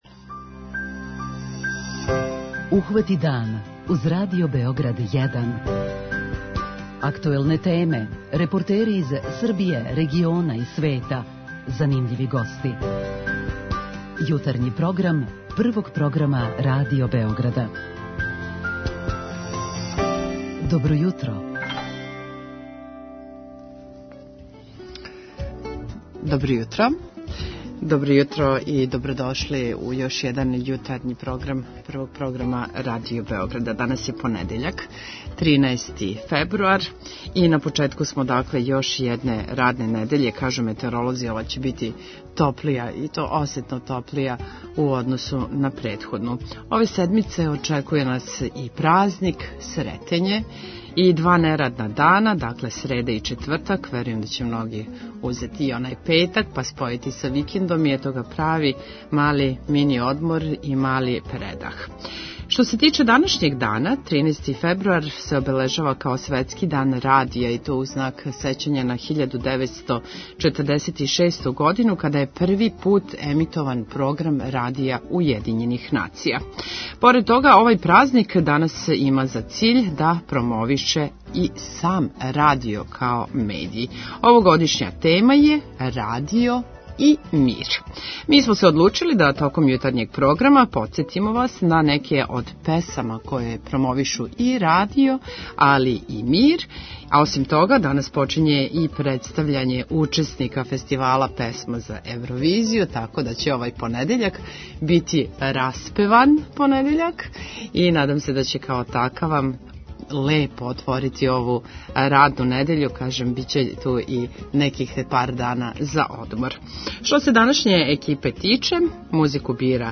Овогодишња тема је радио и мир. Док хватамо дан подсетићемо се неких од највећих хитова који промовишу мир. Ово музичко јутро употпуниће и представљање учесника „ Песме за Евровизију“.